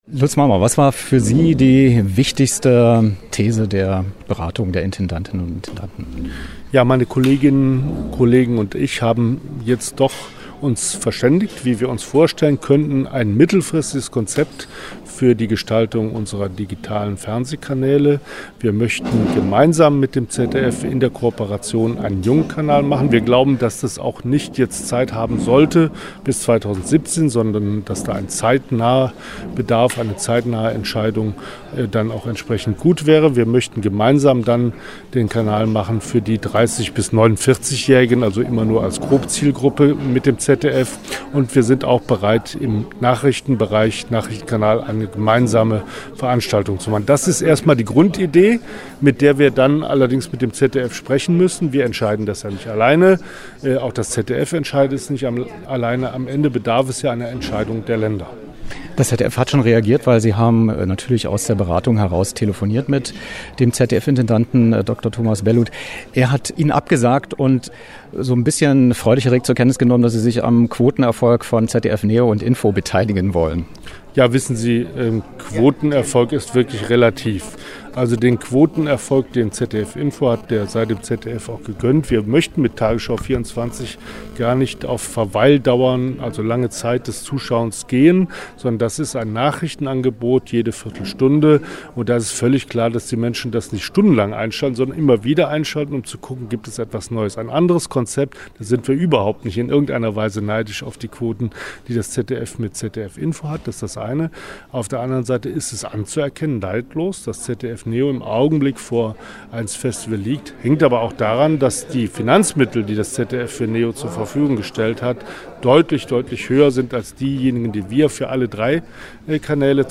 Wer: Lutz Marmor, NDR-Intendant und ARD-Vorsitzender
Was: Interview über die Zukunft der sechs digitalen ARD/ZDF Spartenkanäle und die Zusammenarbeit mit der Deutschen Welle
Wo: Berlin, NRW-Landesvertretung beim Bund, ARD-Pressekonferenz